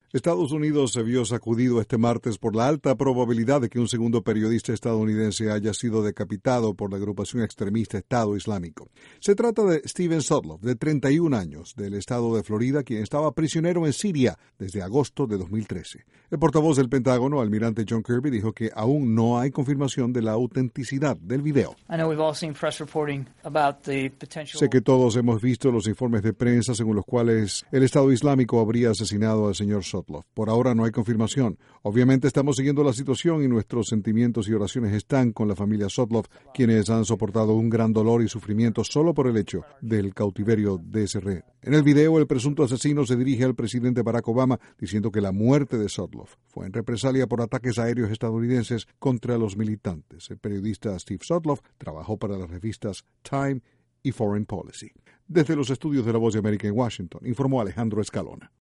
El Pentágono está a la espera de varios análisis de inteligencia que permitan confirmar autenticidad del video del Estado Islámico donde habría sido decapitado otro periodista de EEUU. Desde la Voz de América en Washington informa